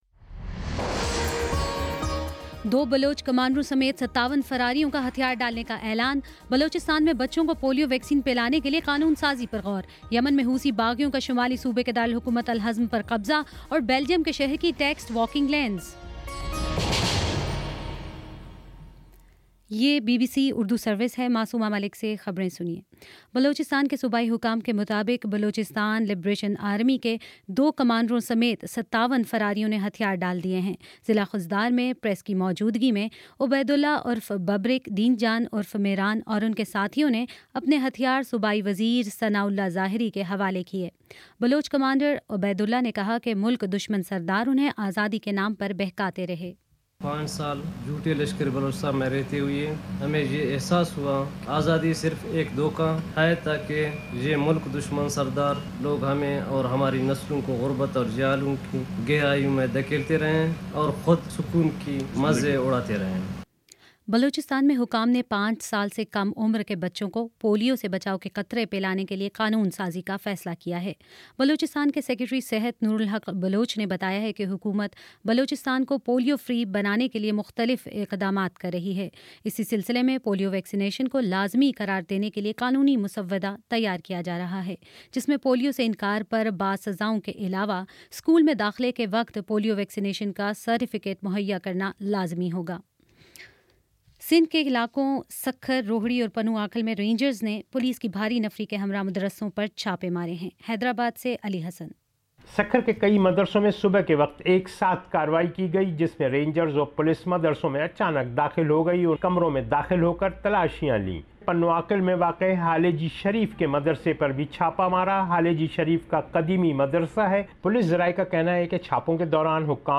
جون 14: شام سات بجے کا نیوز بُلیٹن